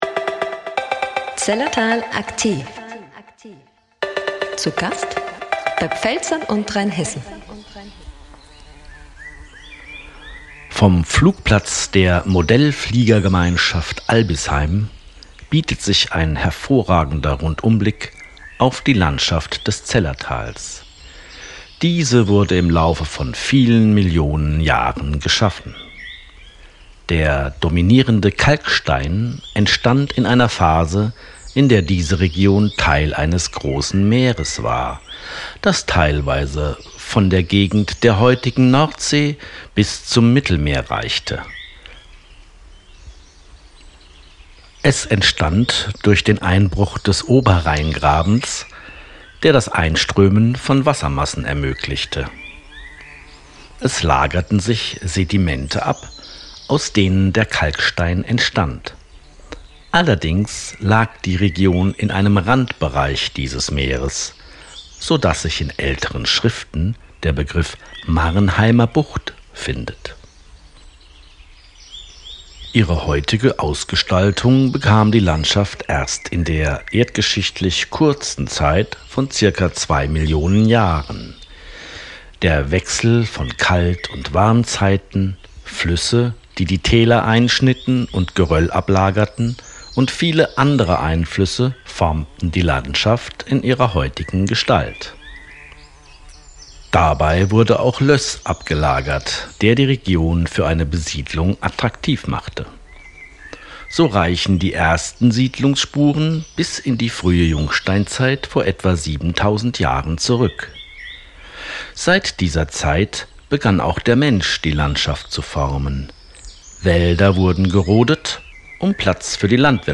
AUDIO-GUIDES